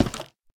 Minecraft Version Minecraft Version 1.21.5 Latest Release | Latest Snapshot 1.21.5 / assets / minecraft / sounds / block / nether_wood_door / toggle3.ogg Compare With Compare With Latest Release | Latest Snapshot